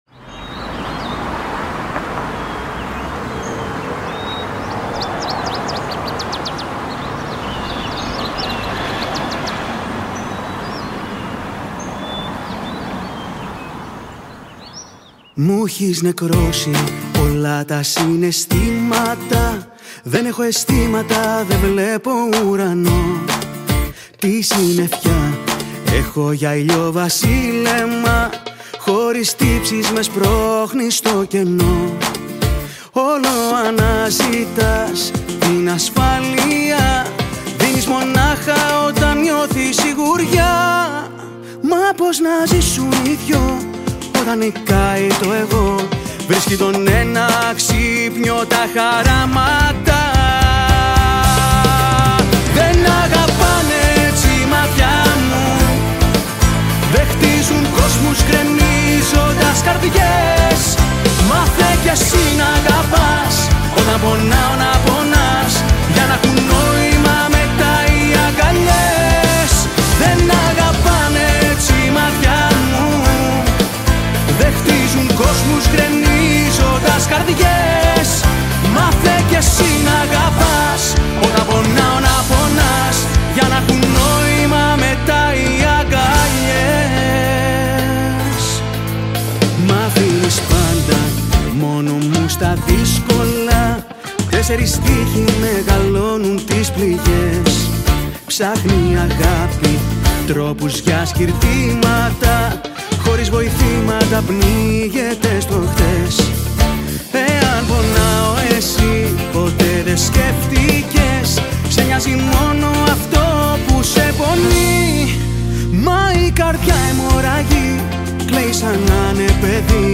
Жанр: ΕΙΔΟΣ ΛΑΪΚΆ / ΣΥΓΧΡΟΝΗ
PROGRAMMING, ΚΙΘΆΡΑ, ΠΛΉΚΤΡΑ, ΤΖΟΎΡΑ
ΚΛΑΡΊΝΟ, ΖΟΥΡΝΆ
ΚΡΟΥΣΤΆ